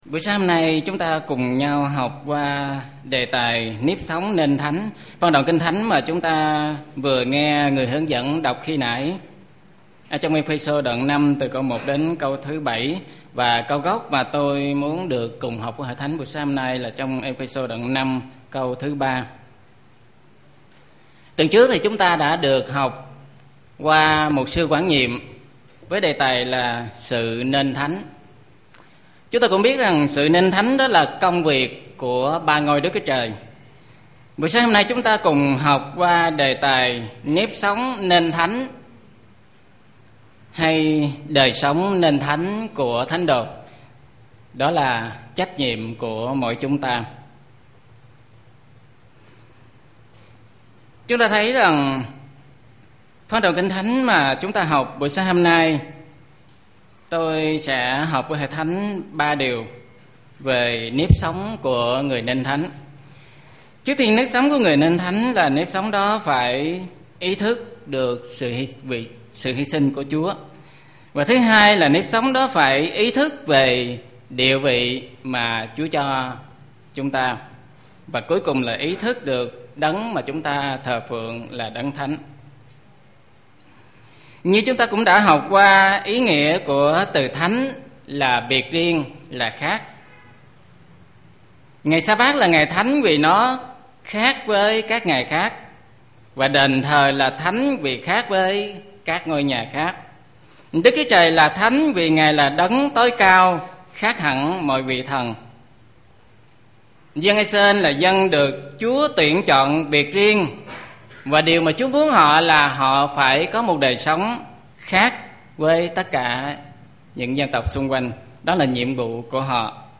NẾP SỐNG NÊN THÁNH - Bài giảng Tin Lành